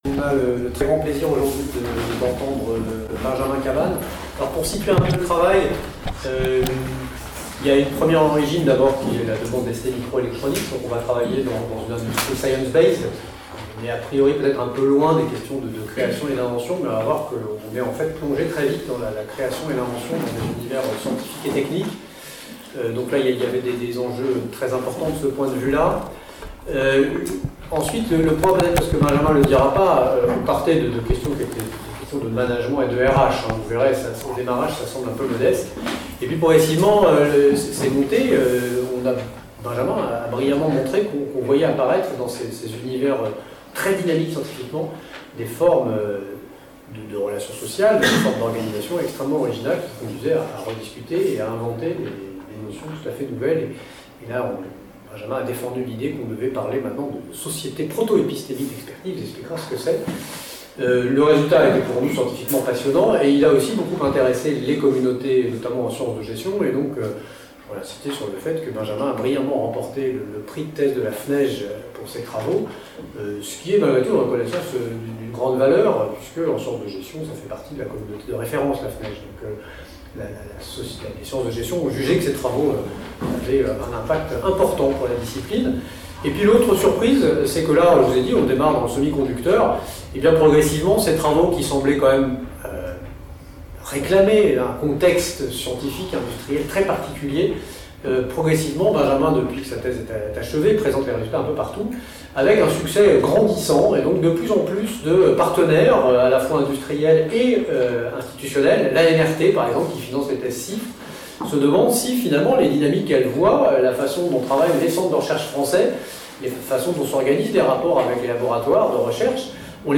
Vous trouverez ici l’enregistrement sonore de l’exposé, des questions et le support .pdf qui a servi l’exposé.